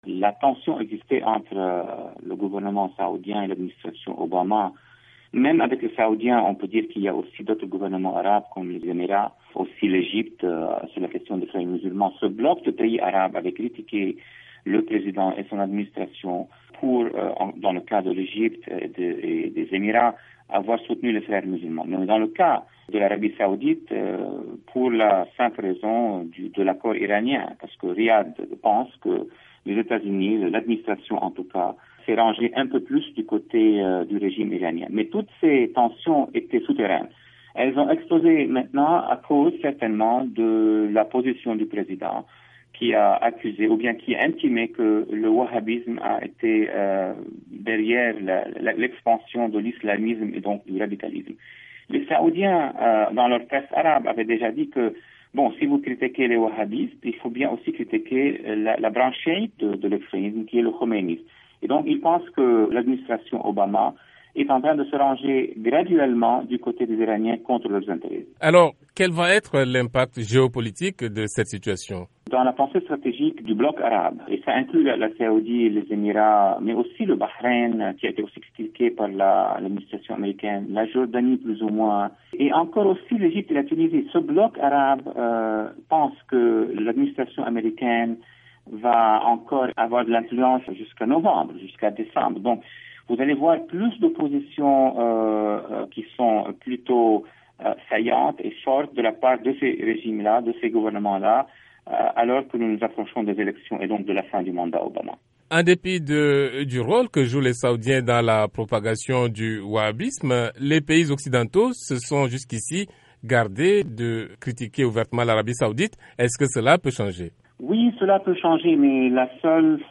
Un entretien avec Walid Phares de la National Defense University de Washington